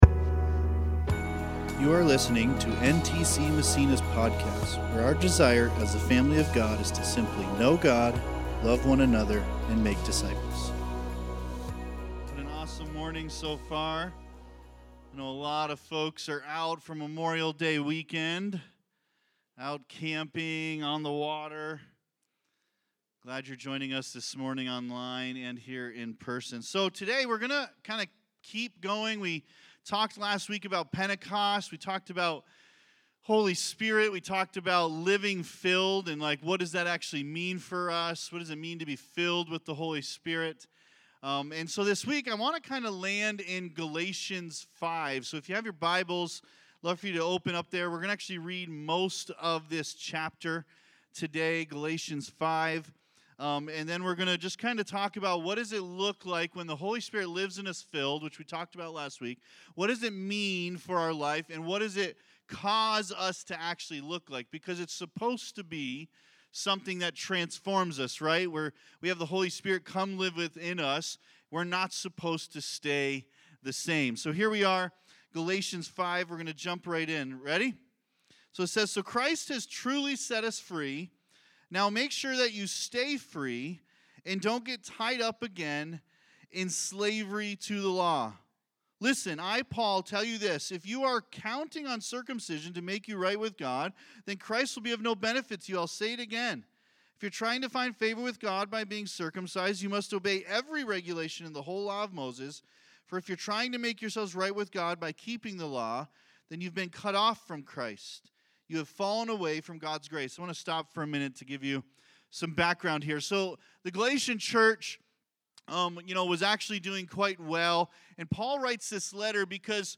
This Sunday at NTC Massena, we had 8 Water Baptisms!